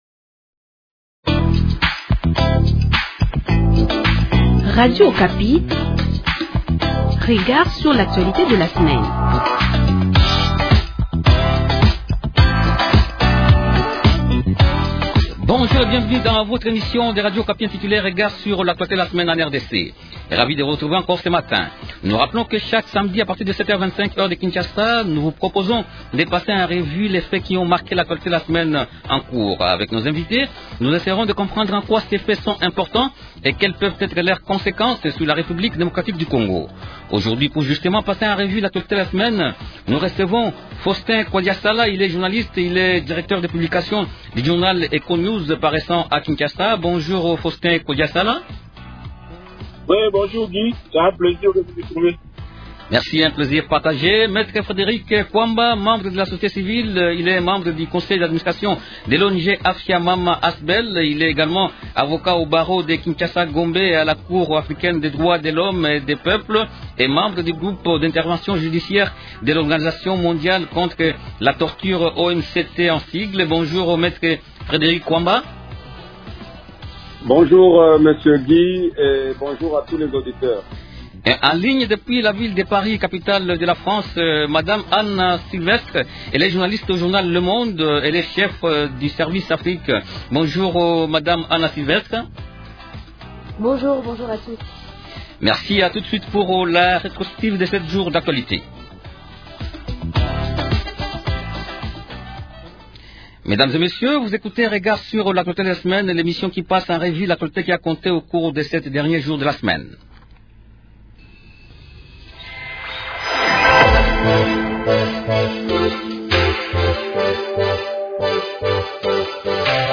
Trois sujets principaux au menu de cette émission :